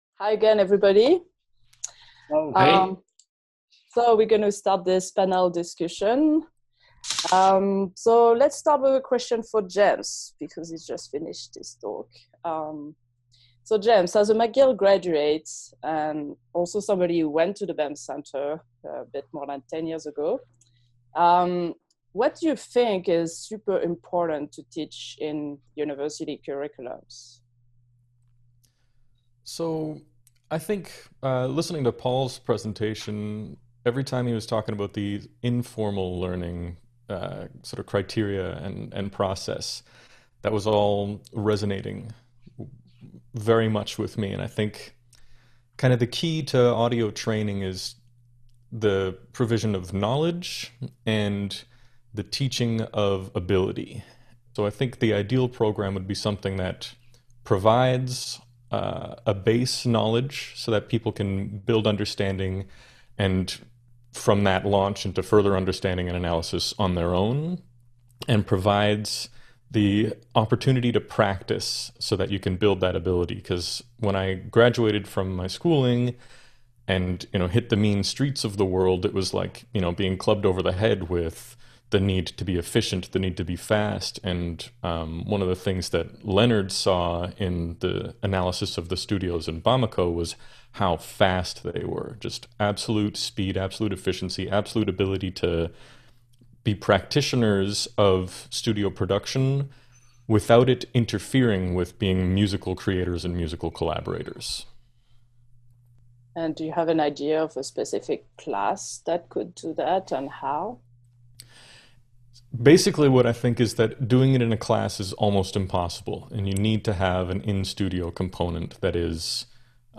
Panel discussion | Canal U